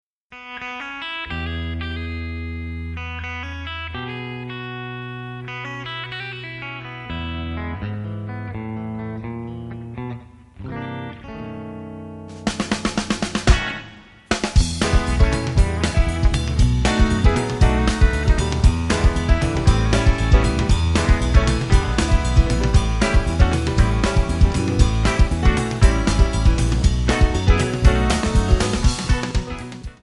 Backing track files: Medleys (30)
Buy With Backing Vocals.